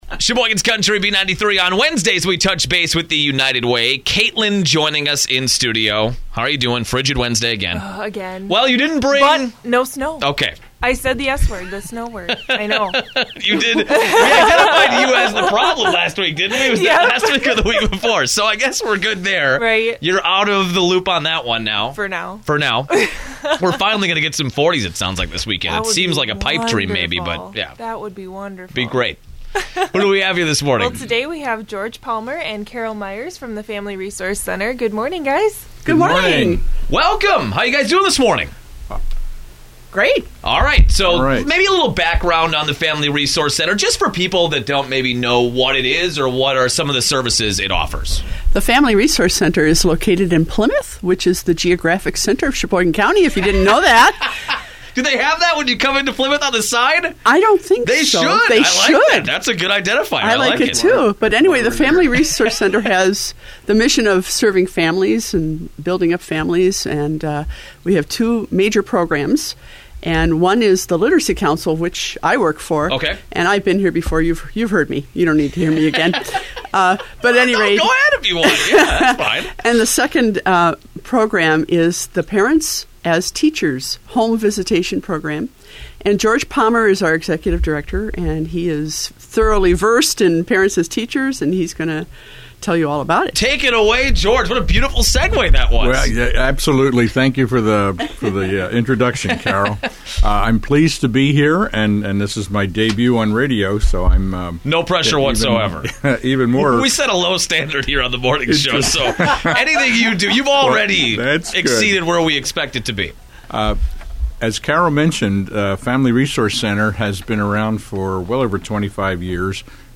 Family Resource Center - Radio Spot